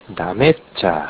下関弁辞典
発音